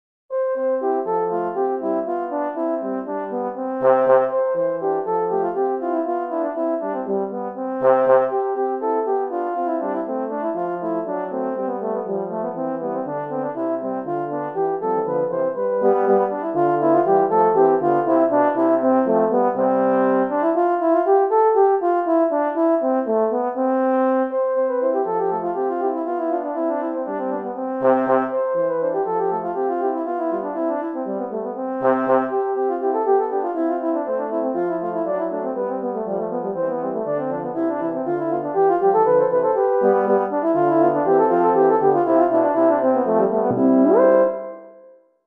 Voicing: Horn Quartet